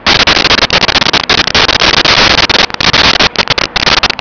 Sfx Amb Loadingdock Loop
sfx_amb_loadingdock_loop.wav